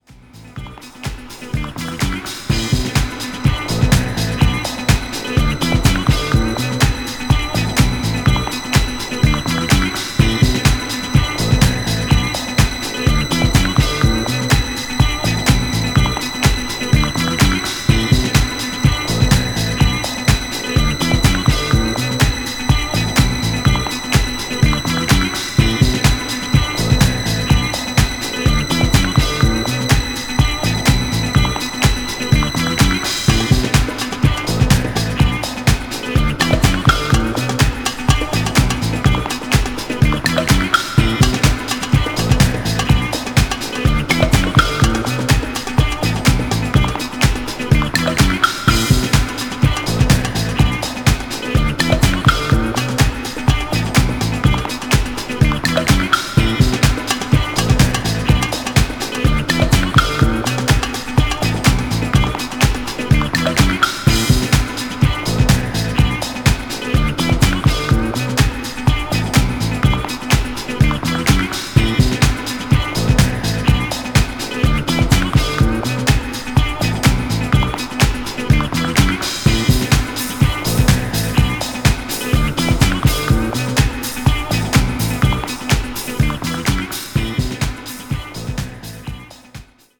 半永久的なディスコループに吸い込まれていく